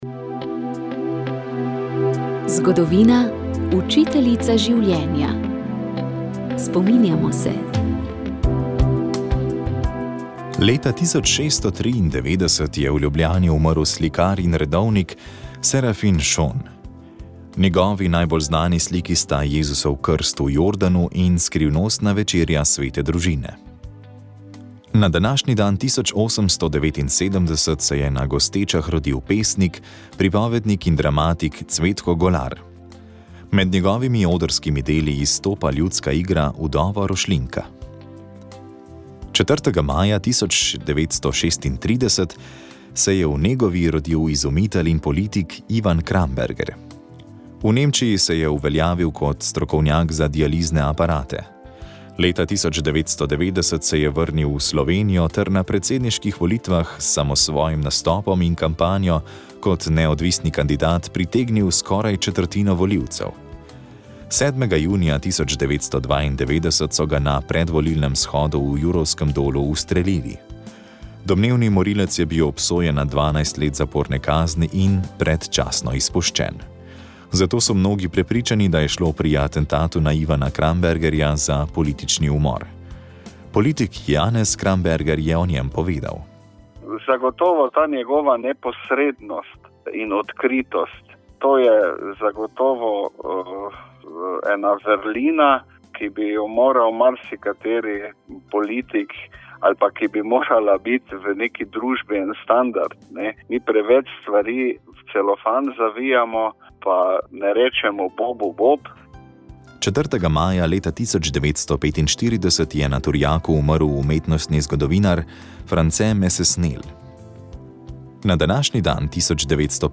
Z njim smo se pogovarjali o spominih na mladost v Savinjski dolini, o slikarstvu in restavratorstvu. Kakšno besedo smo namenili tudi teku, kajti večkrat je pretekel originalni maraton v Grčiji.